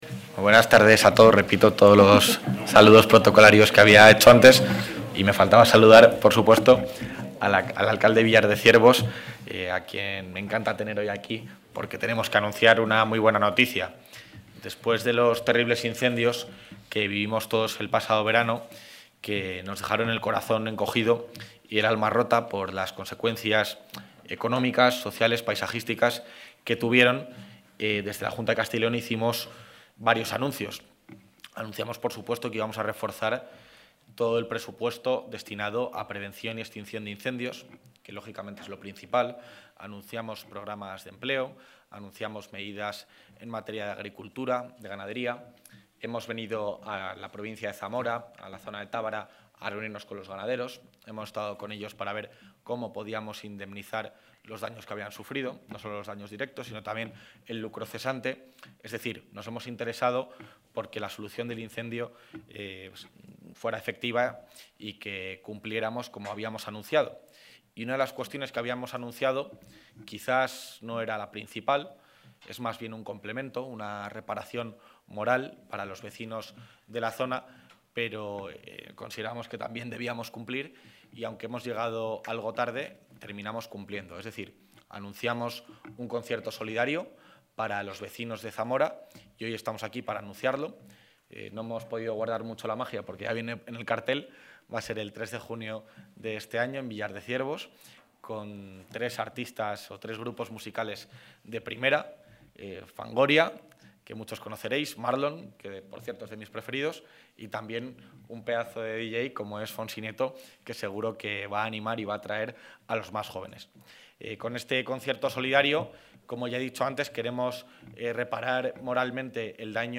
El vicepresidente de la Junta, Juan García-Gallardo, ha presentado hoy en el Museo Etnográfico de Castilla y León de Zamora el...
Intervención del vicepresidente de la Junta.